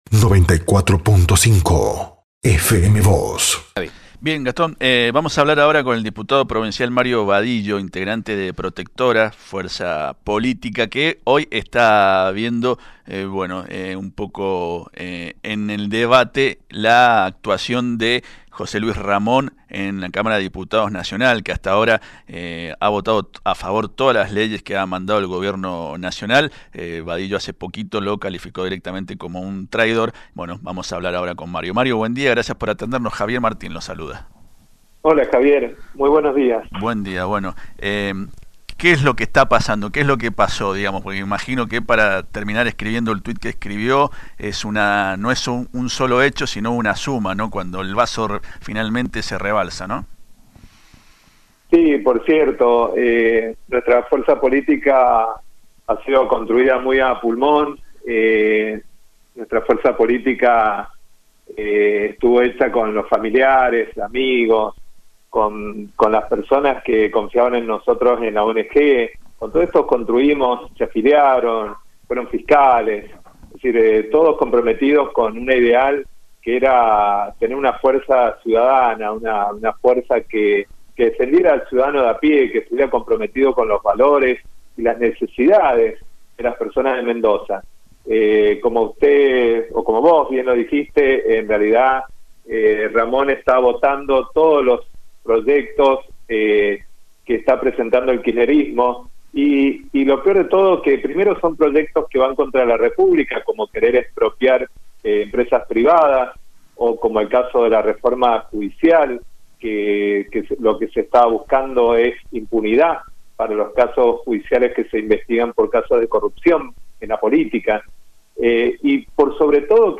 En diálogo con FM Vos (94.5) y Diario San Rafael, afirmó que sería más digno que se fuera con el kirchnerismo y no que planee, como piensan en Protectora, una fórmula conjunta.